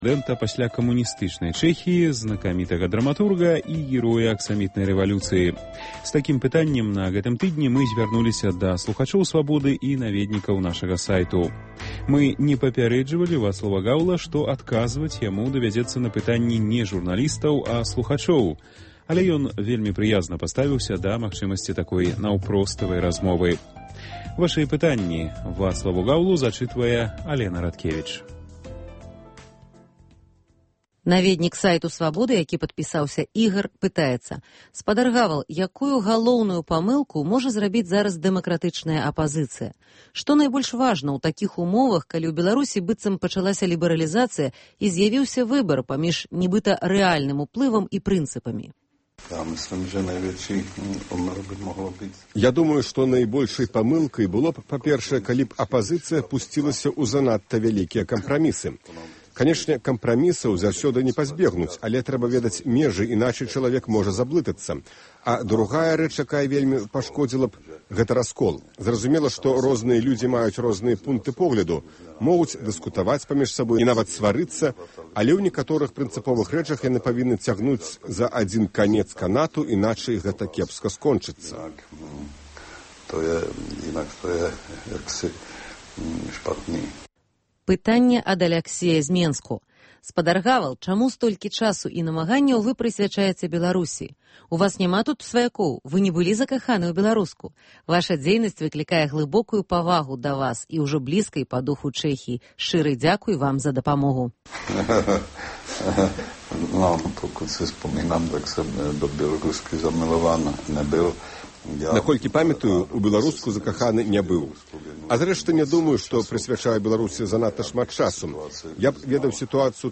Першы прэзыдэнт Чэхіі Вацлаў Гавал адказвае на пытаньні наведнікаў нашага сайту, слухачоў і журналістаў Радыё Свабода.